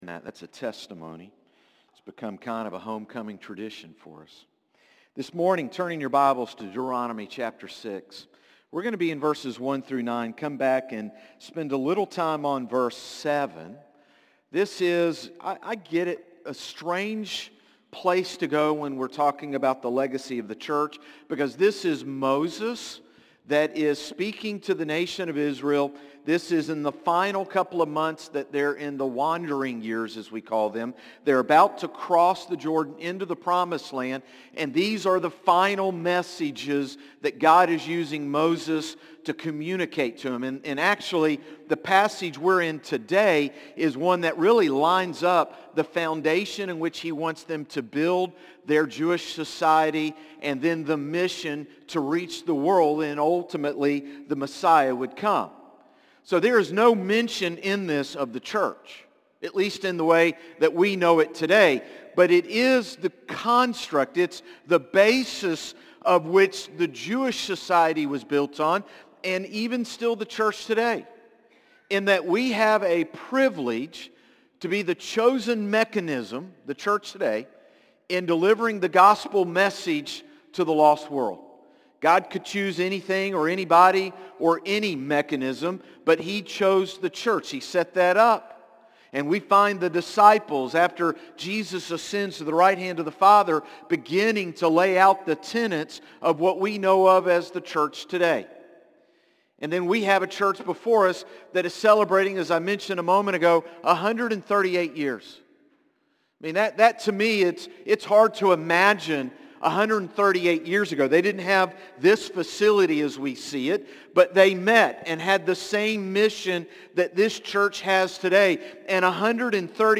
Sermons - Concord Baptist Church
Morning-Service-5-18-25.mp3